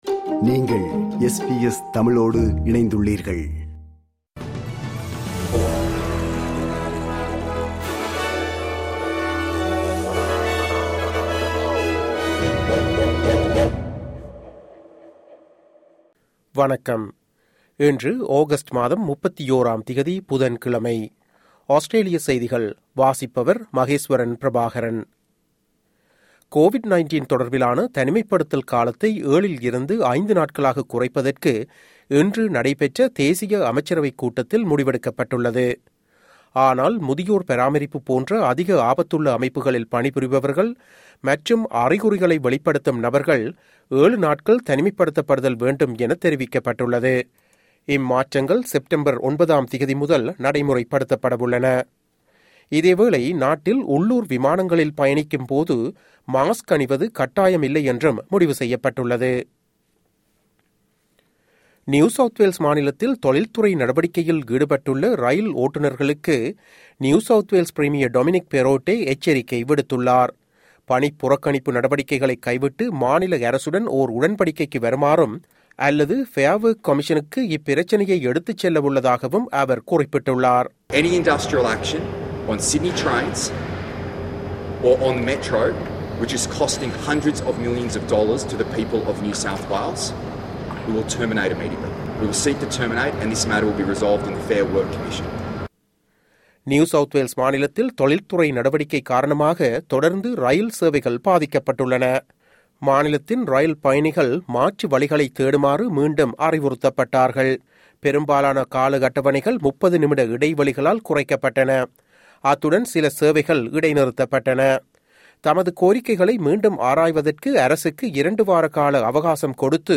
Australian news bulletin for Wednesday 31 August 2022.